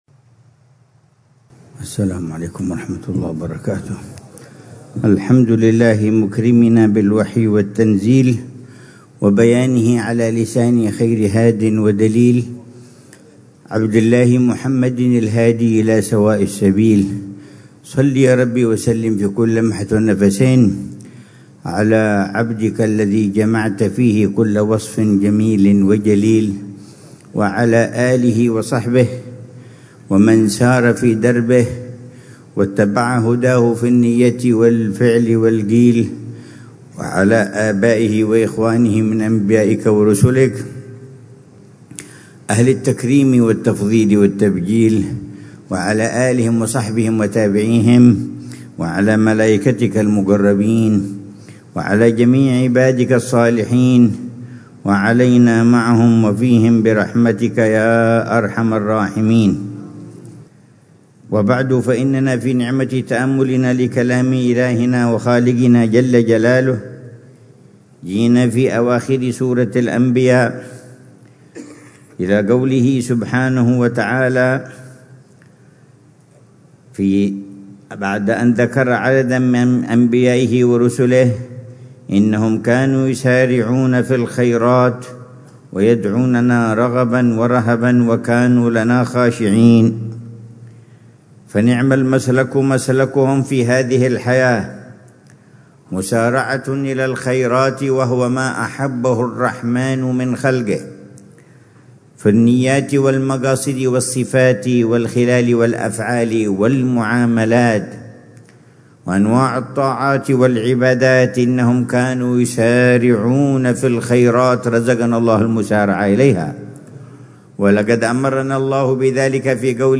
تفسير فضيلة العلامة الحبيب عمر بن محمد بن حفيظ للآيات الكريمة من سورة الأنبياء